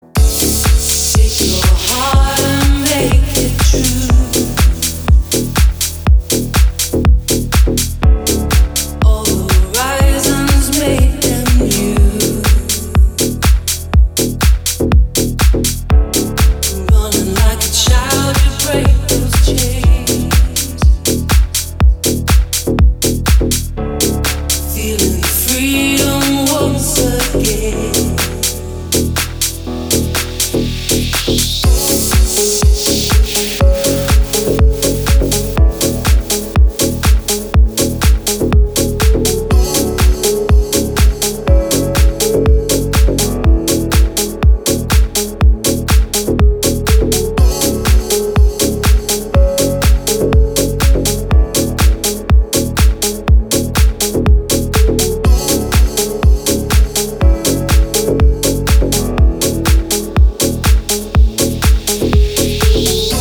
• Качество: 320, Stereo
женский вокал
deep house
релакс